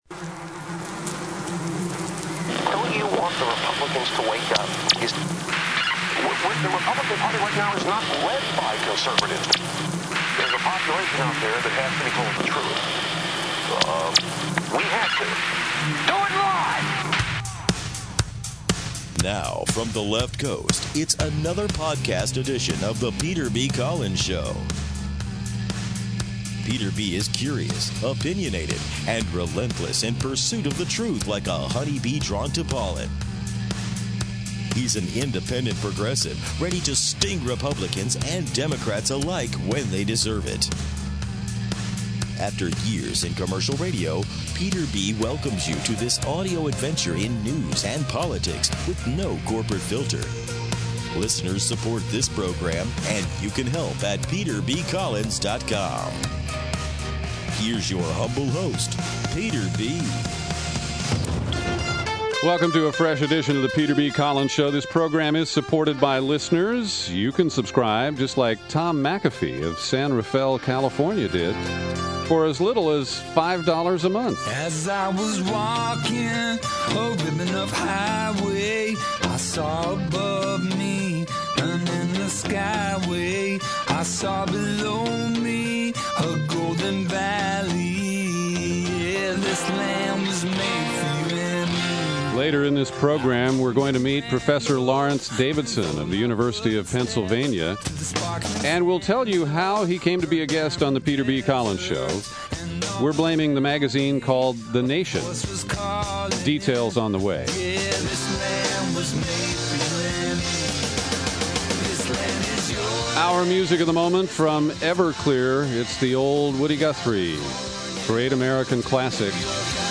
This is the latest installment in the Boiling Frogs interview series, co-hosted with Sibel Edmonds.